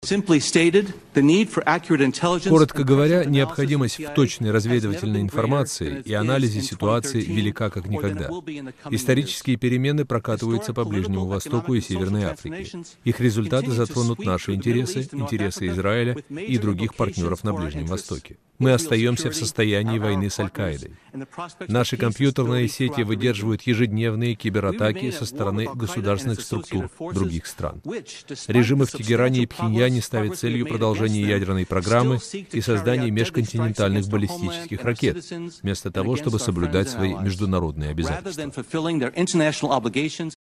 Фрагмент выступления Джона Бреннана в Сенате США